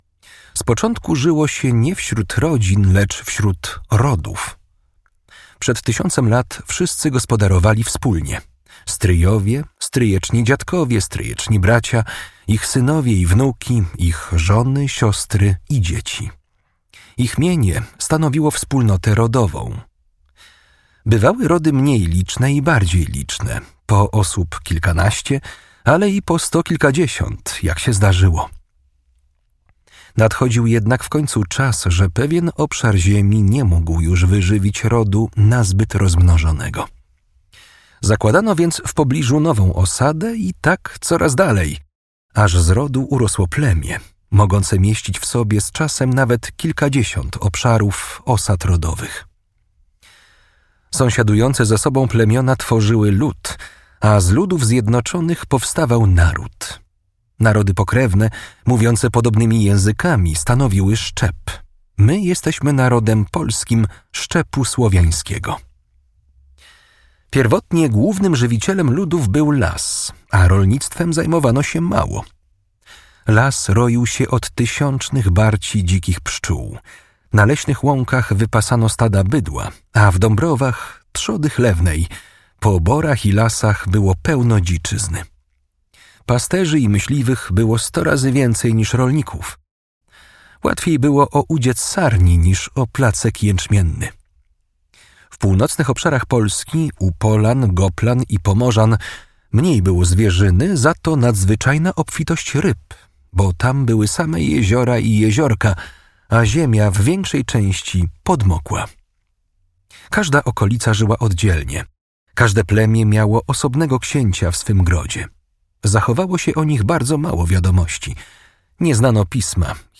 audiobook + książka